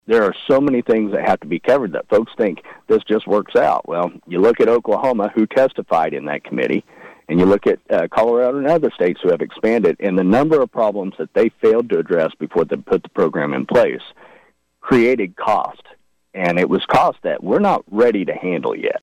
Smith joined KVOE’s Morning Show Thursday to conclude KVOE’s annual legislative previews and says when it comes to the budget, he is pleased with where the state stands, especially after December tax collections topped estimates by almost 15 percent.